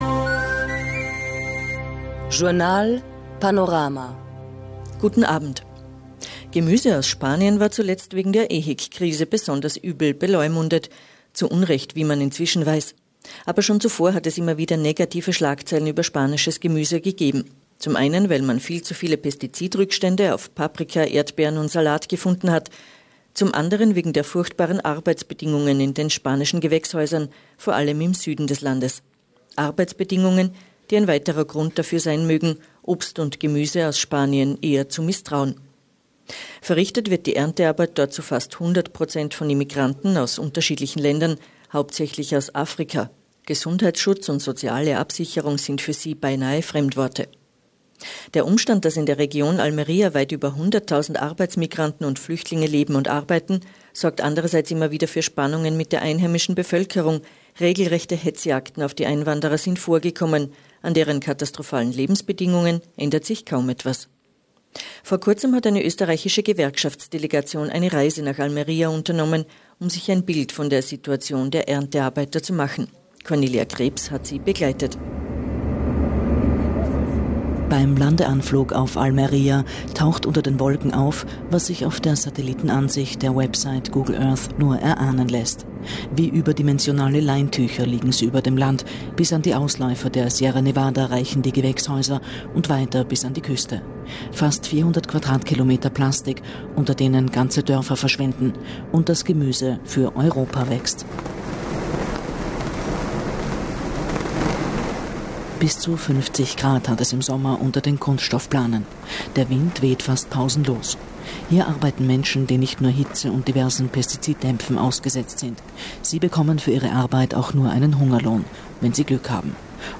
Gemeint ist die AUsbeutung der Migranten als Erntehelfer und Landarbeiter, die ein Spiegelbild der Sklaverei darstellen, wie es seit mehr als 150 Jahren nicht mehr gesehen wurde. Hier ist der Bericht zum Nachhören.